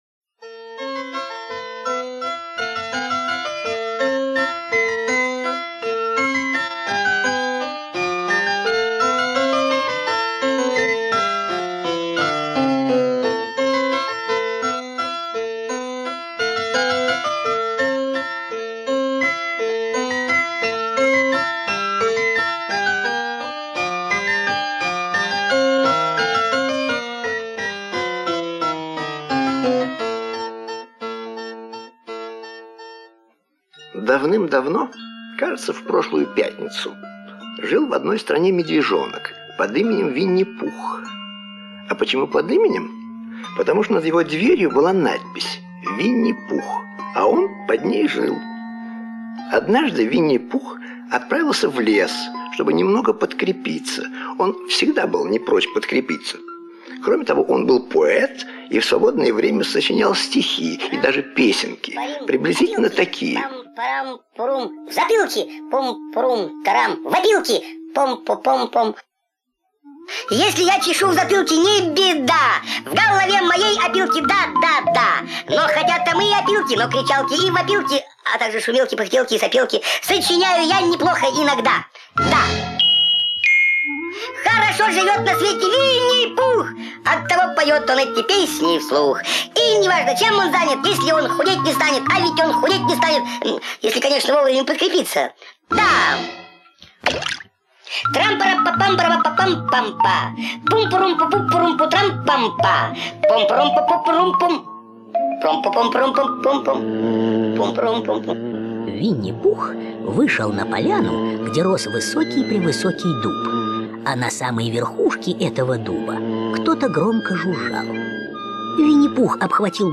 Винни-Пух и пчелы - аудиосказка Милна - слушать онлайн